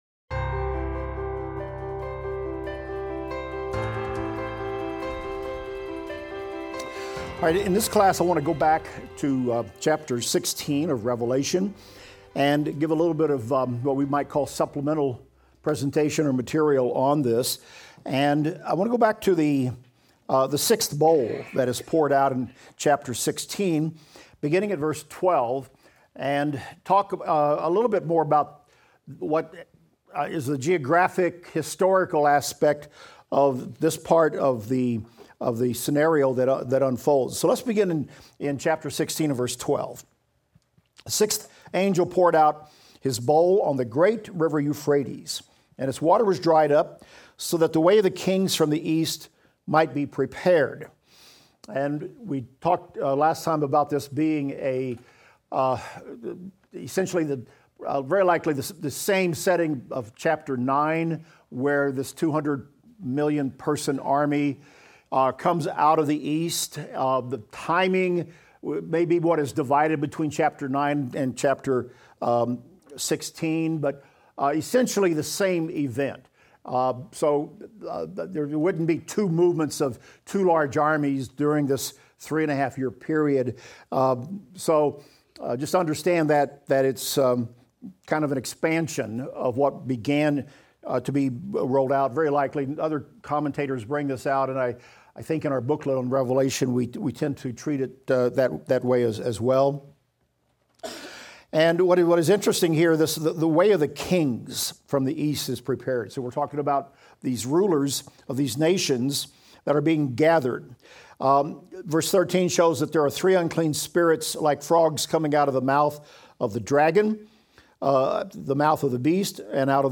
Revelation - Lecture 47 - audio.mp3